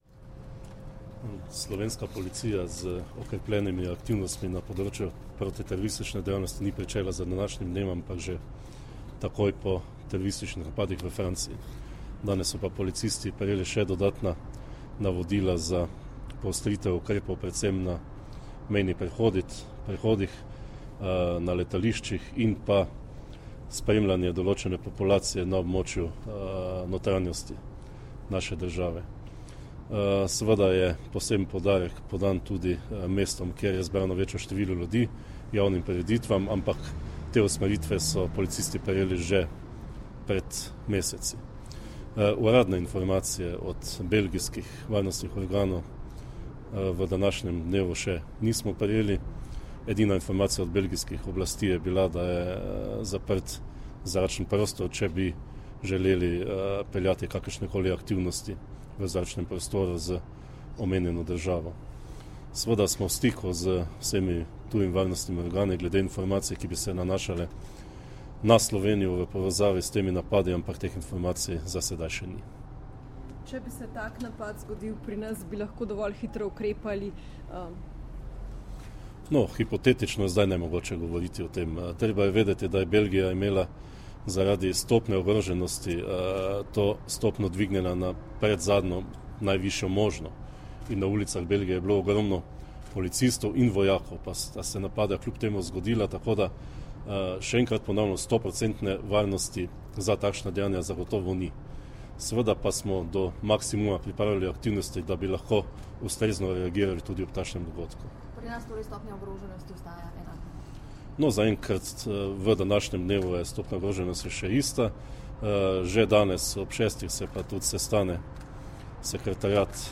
Več o tem je v današnji izjavi za medije povedal generalni direktor policije Marjan Fank
Zvočni posnetek izjave generalnega direktorja policije Marjana Fanka (mp3)